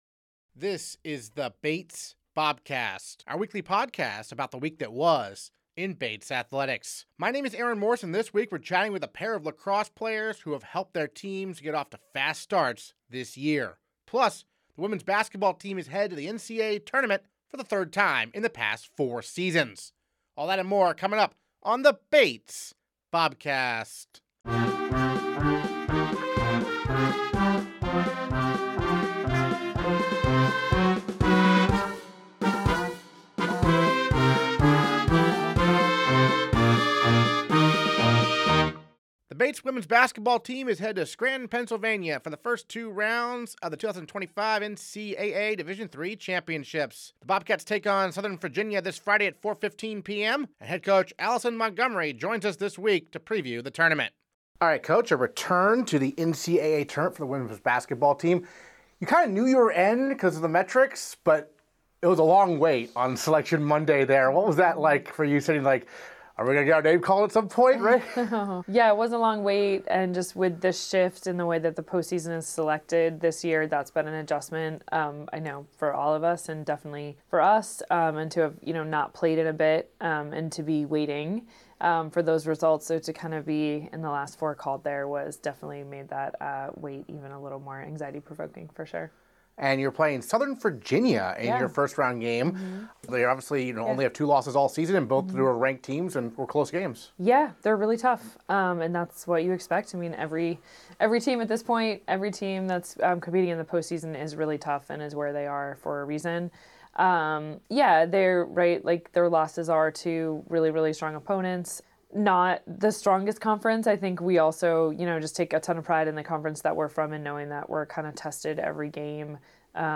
This week we're chatting with a pair of lacrosse players who have helped their teams get off to fast starts this year. Plus, the Bates women's basketball team is headed to the NCAA tournament for the third time in the last four seasons.
Interviews this episode: